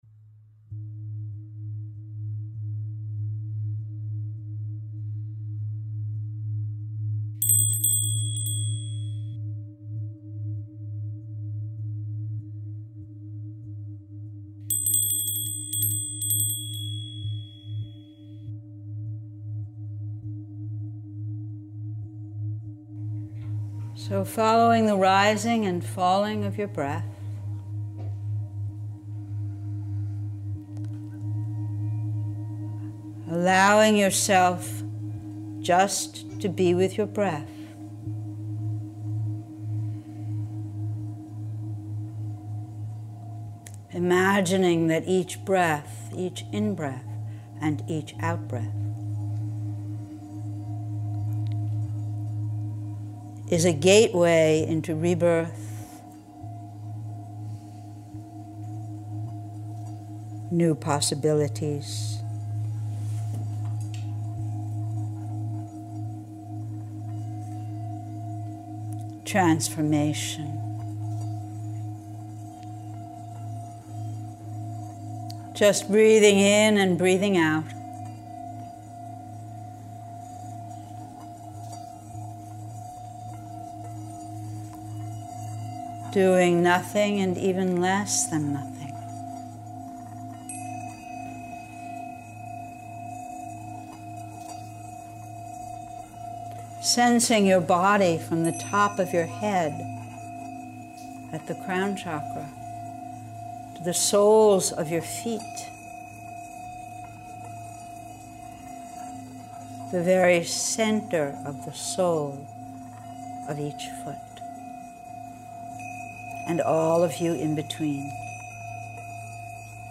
Audio Meditations
They are recorded live in class.